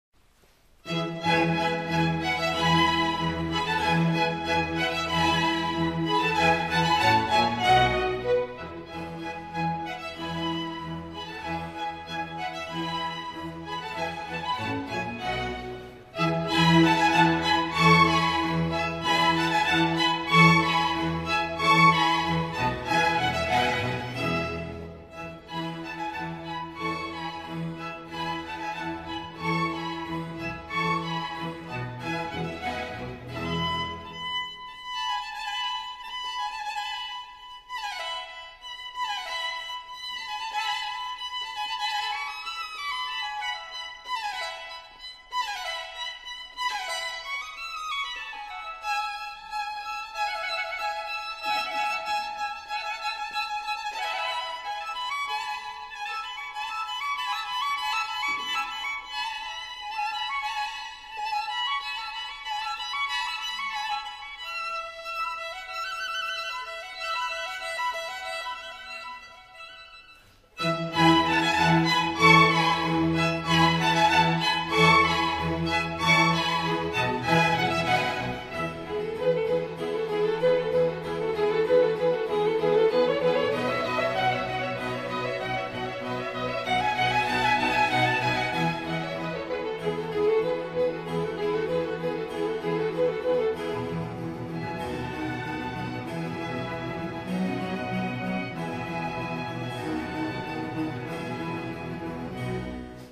antonio-vivaldi-primavera-allegro-violin-las-4-estaciones-audiotrimmer.com_.mp3